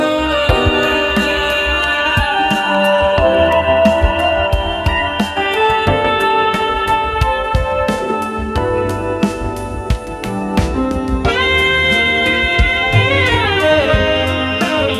smooth_jazz_evening.wav